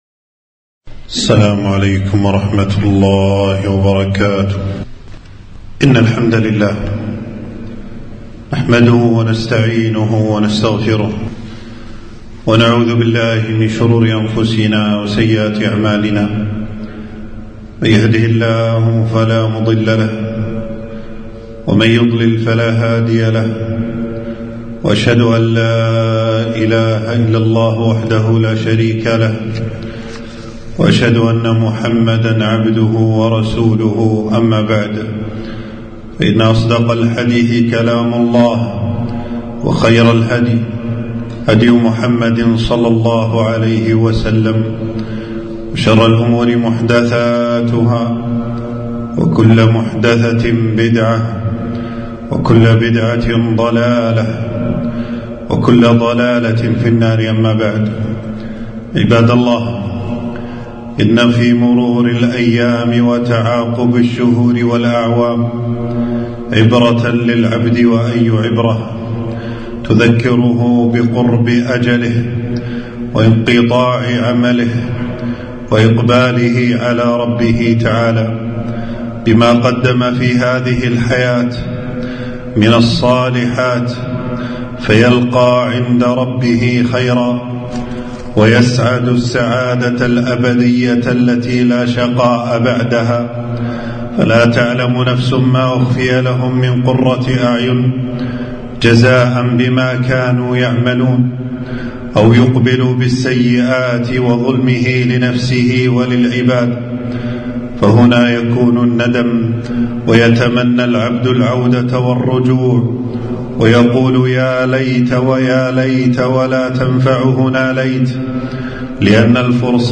خطبة - نهاية العام الهجري تذكرة لمن اعتبر